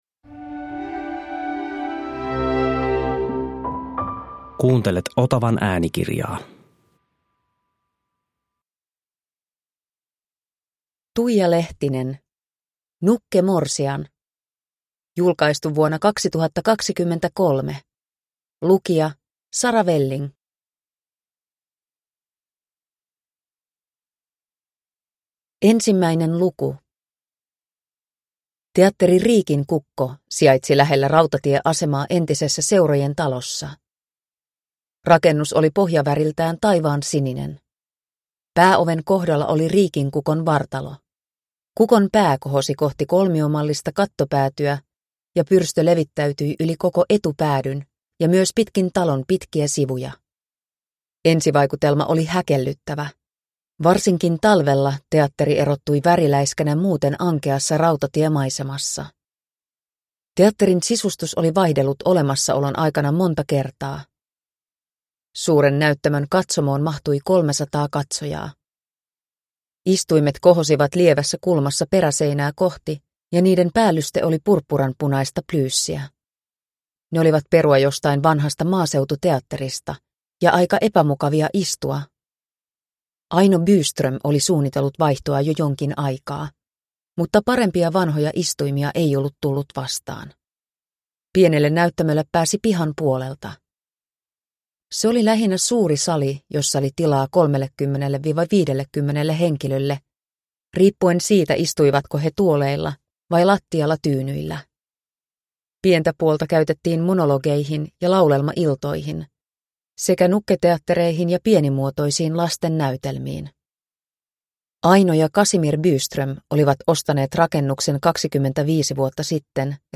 Nukkemorsian (ljudbok) av Tuija Lehtinen